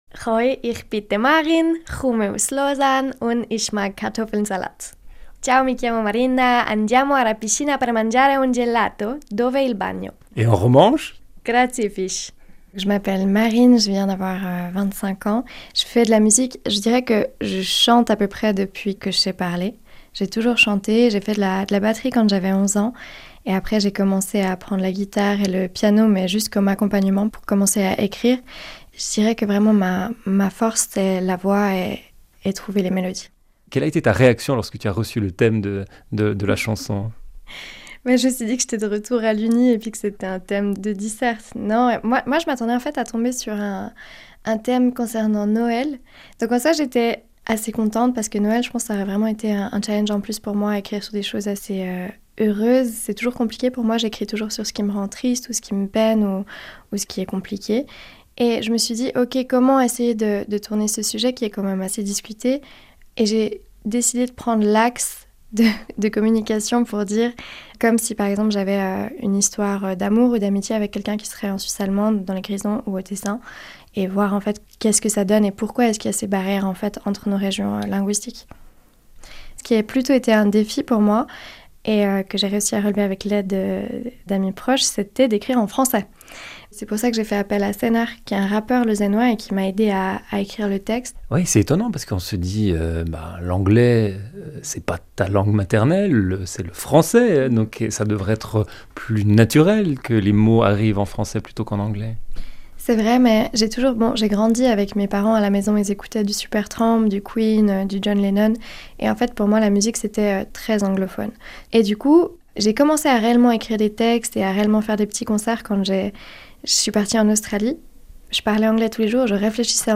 Le 4 nuove canzoni, una in italiano, una in romancio, una in svizzero tedesco e una in francese saranno presentate durante il programma con interviste, retroscena e storie dai 4 angoli del Paese.